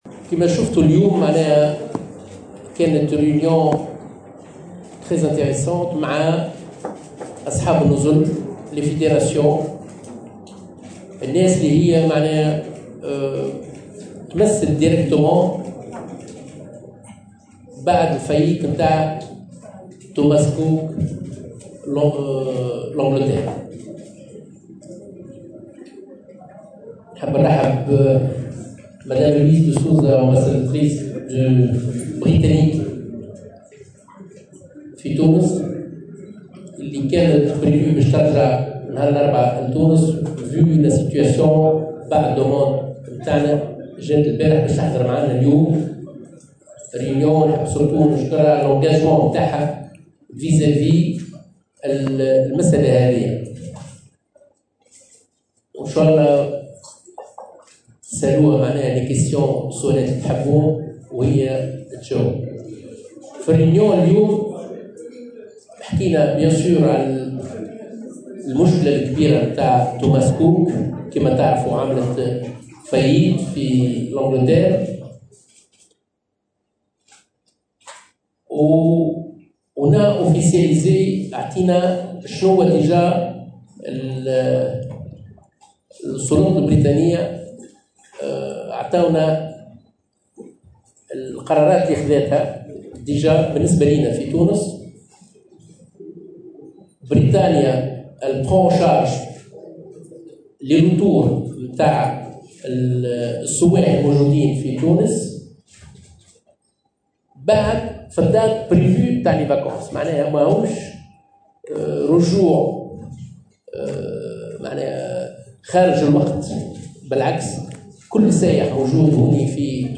وأشار الطرابلسي، في تصريحات للصحفيين، خلال ندوة صحفية، حضرتها سفيرة بريطانيا بتونس لويزا دي سوزا عقبت لقاء ثنائيا ، الى أن ما بين 40 و45 نزلا في تونس سيتضرر من افلاس "توماس كوك ".